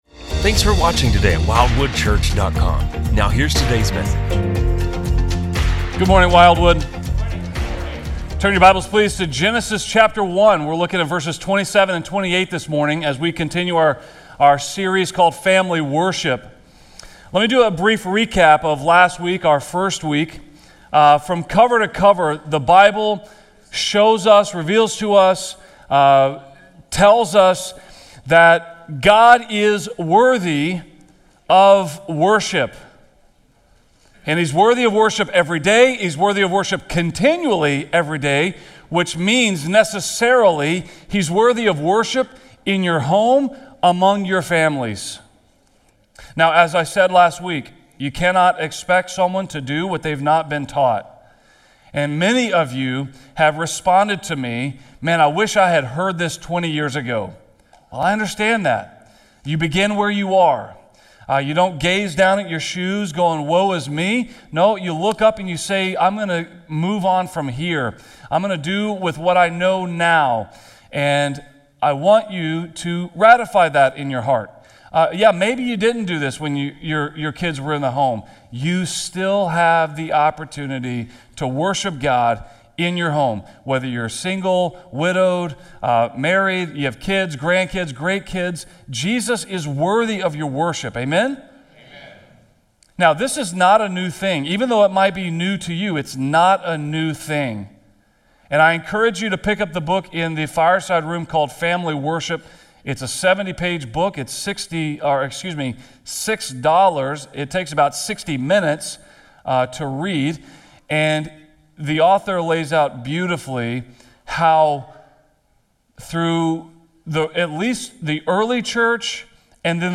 Drawing from the creation account in Genesis, the sermon explores God’s design for family worship, the devastating consequences of Adam’s failure to guard the Garden, and the redemptive call for fathers today to lead, teach, and protect their families in faith. This is more than a challenge—it’s a call to faithfulness, humility, and legacy - a father’s highest calling!